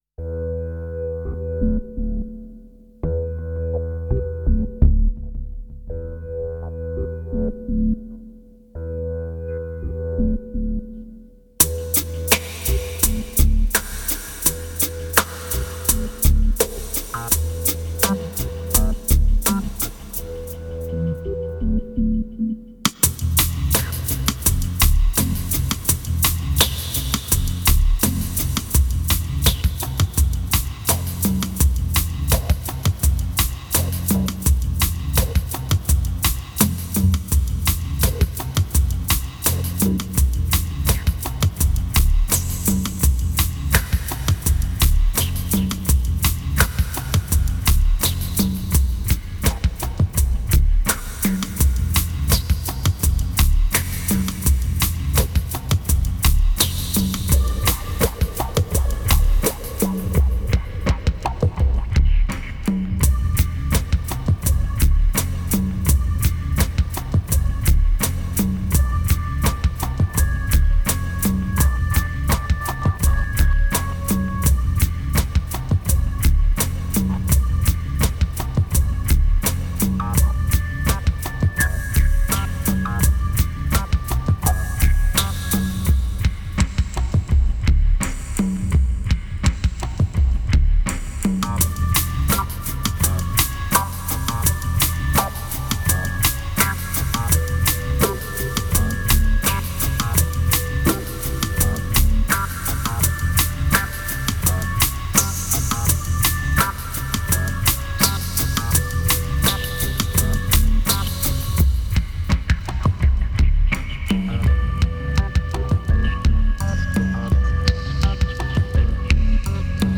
2451📈 - -6%🤔 - 84BPM🔊 - 2009-08-30📅 - -201🌟